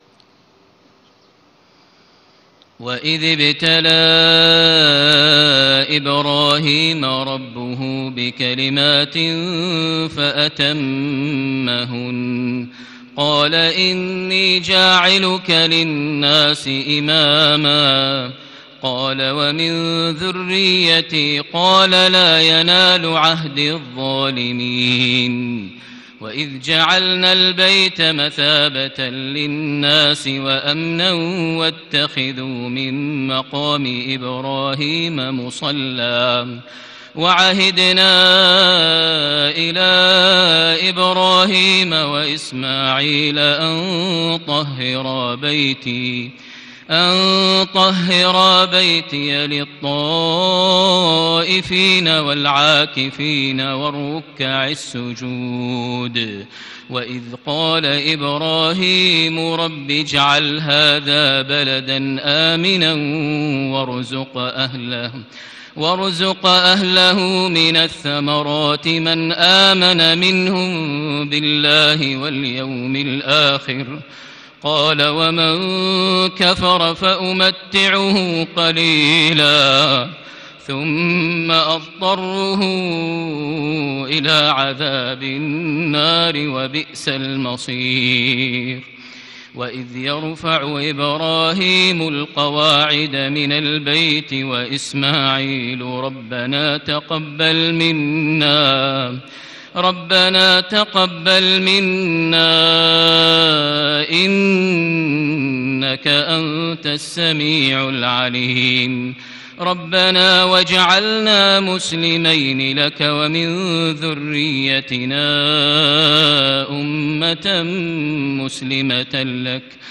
فجر يوم عرفة خاشعة ٩ ذي الحجة ١٤٣٦هـ سورة البقرة ١٢٤-١٤١ > فجريات يوم عرفة > المزيد - تلاوات ماهر المعيقلي